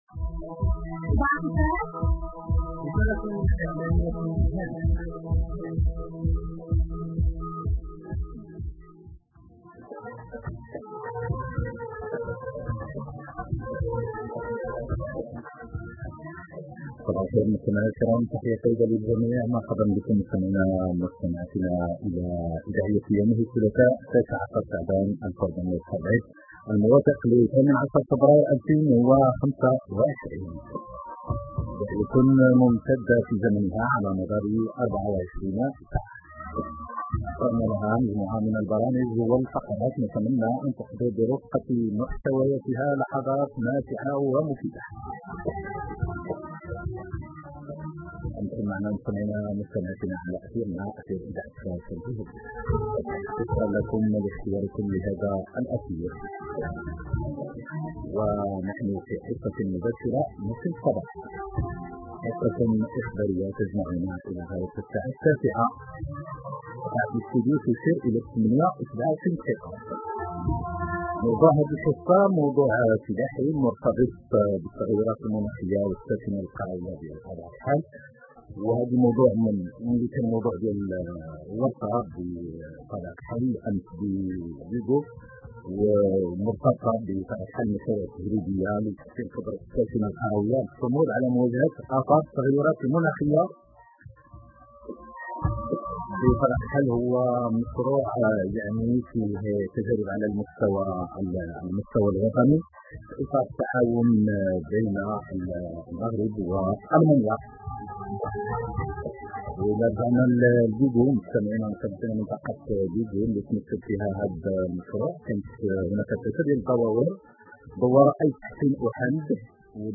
تغطية-اعلامية-بإذاعة-فاس.ناخ-‘‘ما-بين-الوكالة-ا-2.mp3